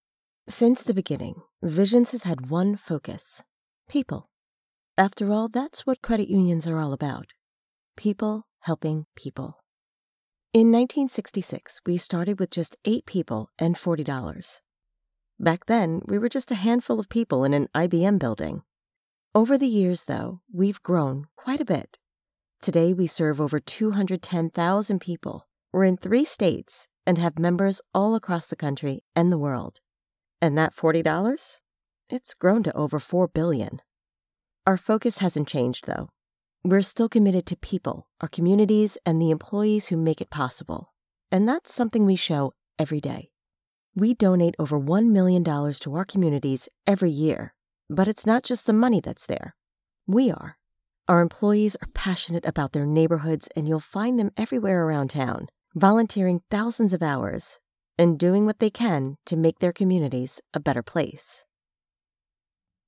Banking Narration http
Banking-Narration.mp3